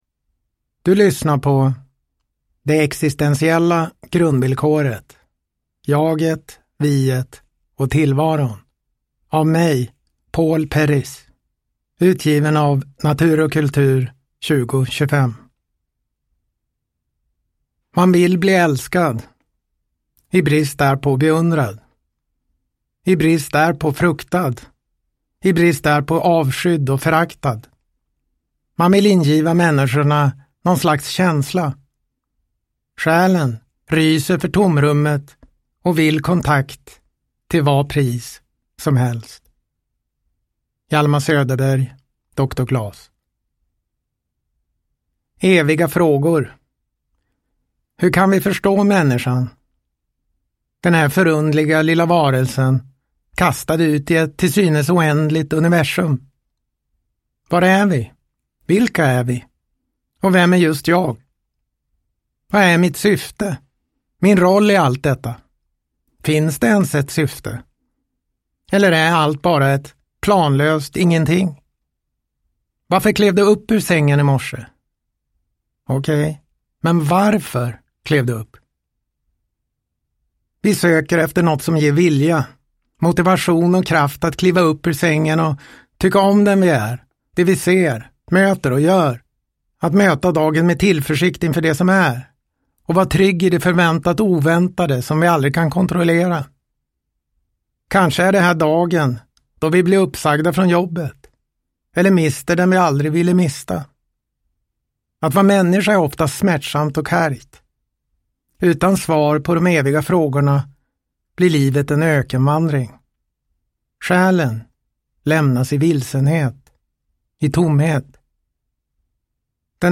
Det existentiella grundvillkoret : Jaget, viet och tillvaron – Ljudbok